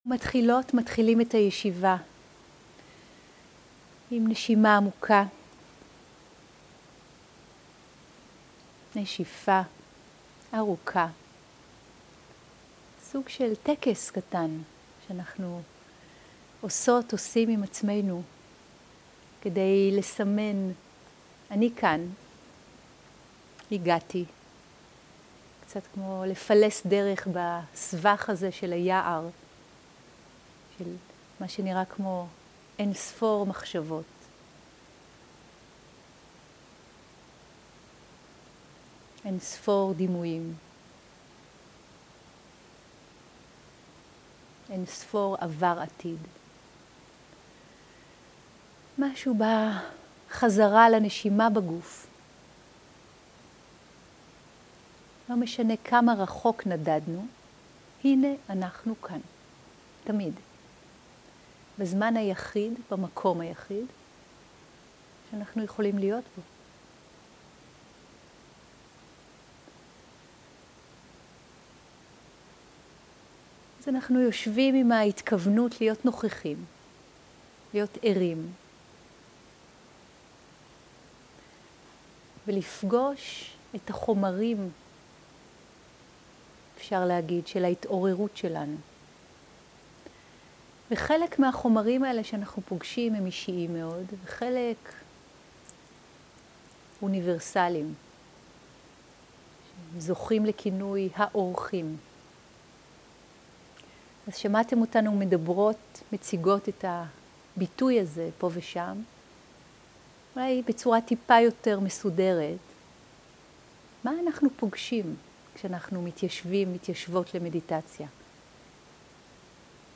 מדיטציה מונחית על אורחים
Dharma type: Guided meditation